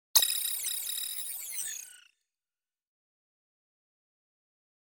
دانلود آهنگ ربات 26 از افکت صوتی اشیاء
جلوه های صوتی
دانلود صدای ربات 26 از ساعد نیوز با لینک مستقیم و کیفیت بالا